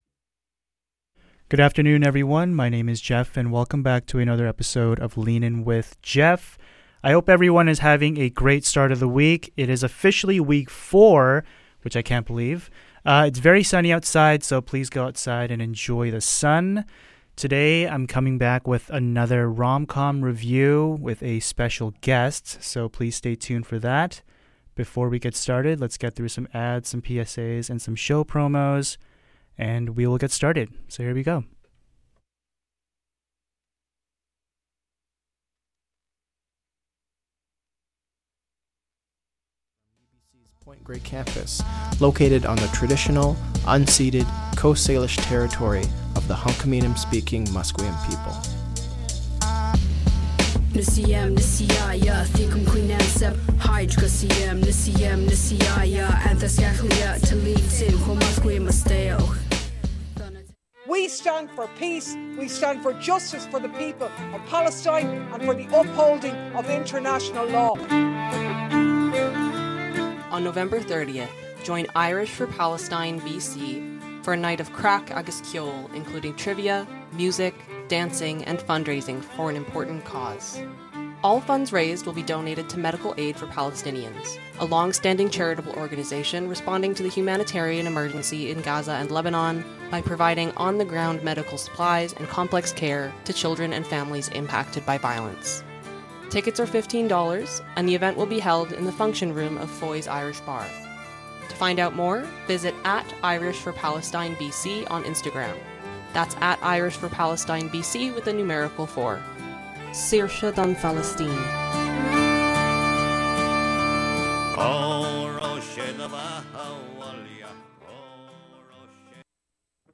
We’ll also discuss the film’s themes of love, sacrifice, and living in the moment, analyzing how they contribute to its lasting impact. Whether you’re a longtime fan or watching for the first time, tune in for an engaging conversation filled with fresh perspectives and fascinating insights!